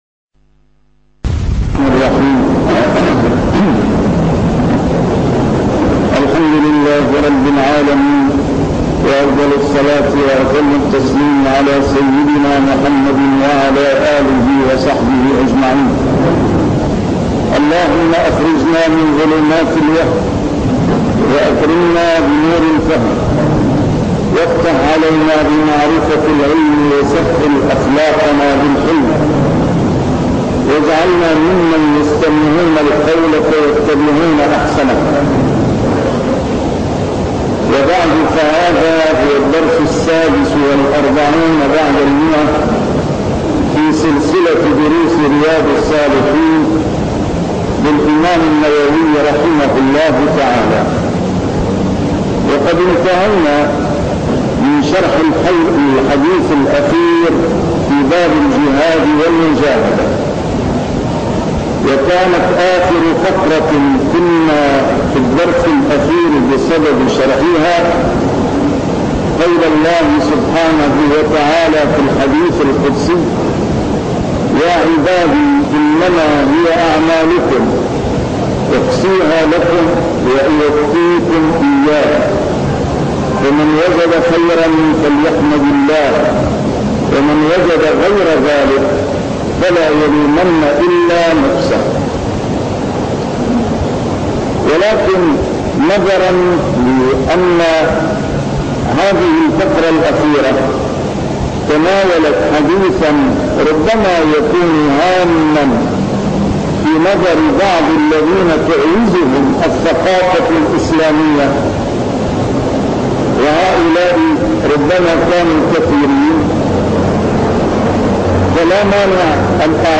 A MARTYR SCHOLAR: IMAM MUHAMMAD SAEED RAMADAN AL-BOUTI - الدروس العلمية - شرح كتاب رياض الصالحين - 146- شرح رياض الصالحين: المجاهدة